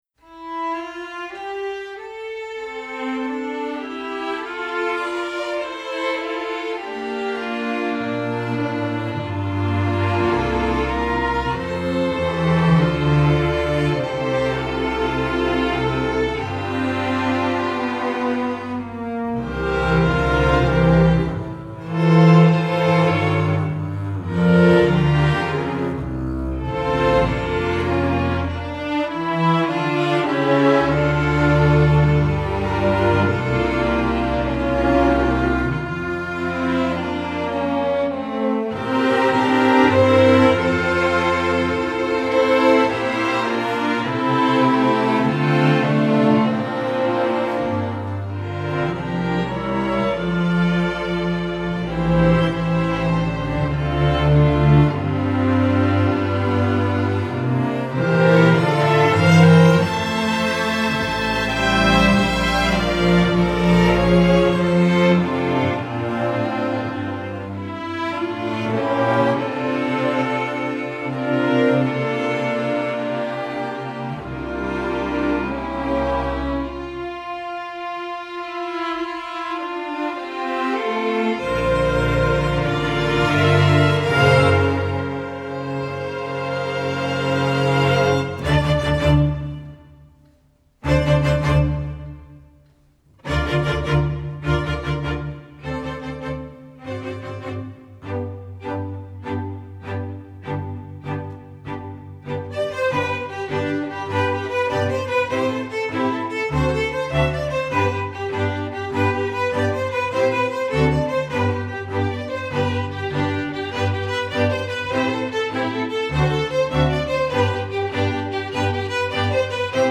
folk, traditional